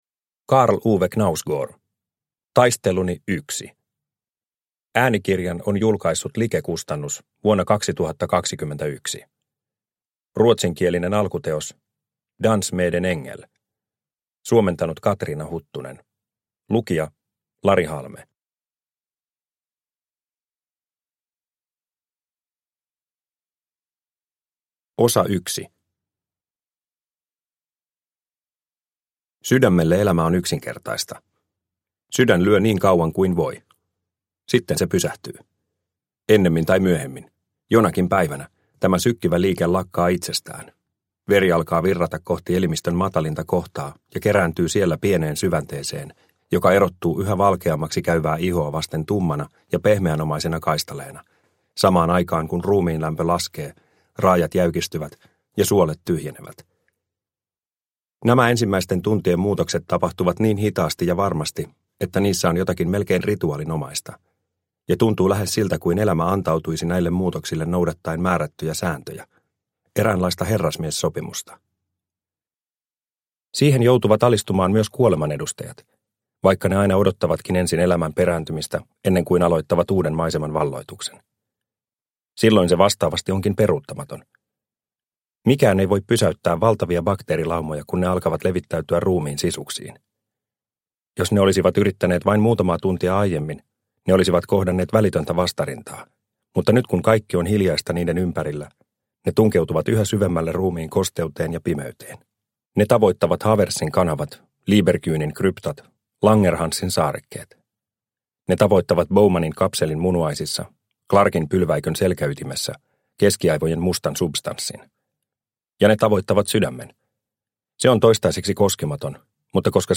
Taisteluni I – Ljudbok – Laddas ner